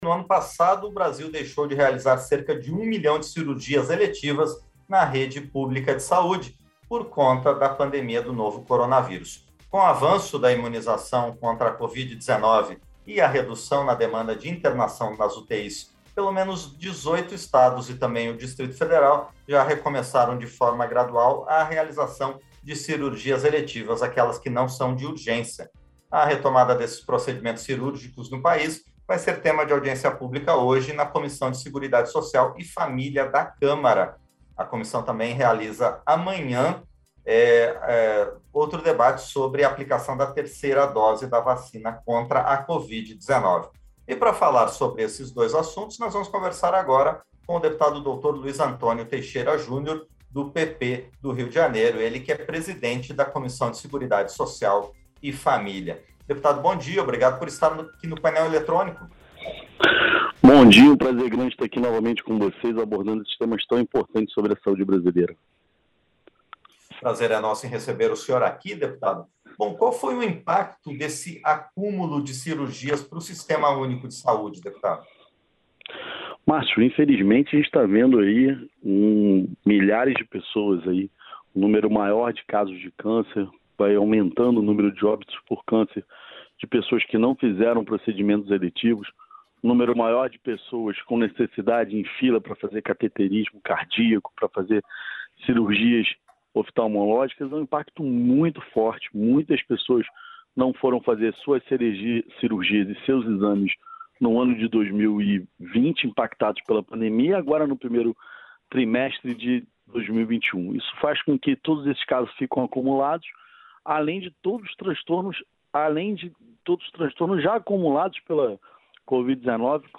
Entrevista - Dep. Dr. Luiz Antônio Teixeira Jr. (PP-RJ)